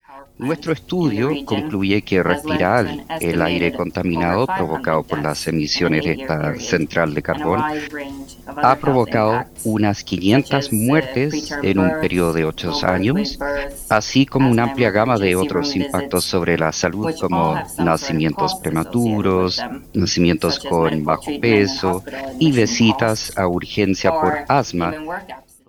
entrevista-en-ingles-cuna-1.mp3